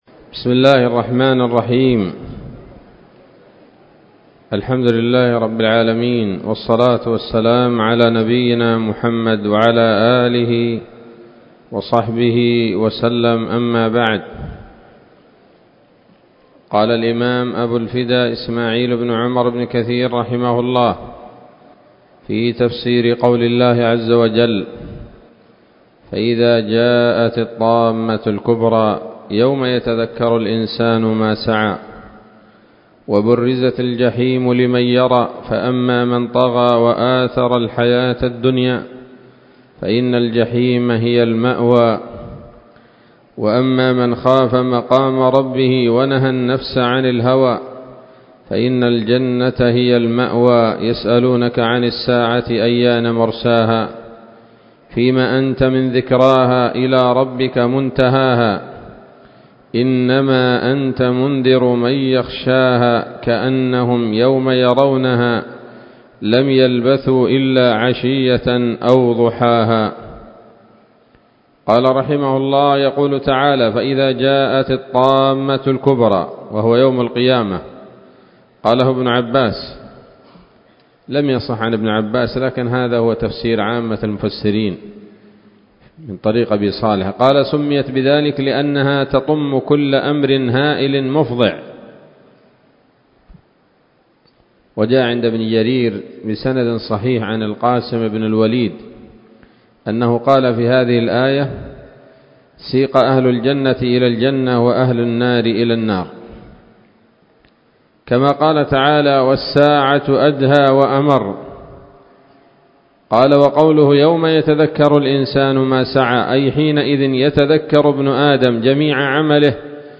الدرس الثالث وهو الأخير من سورة النازعات من تفسير ابن كثير رحمه الله تعالى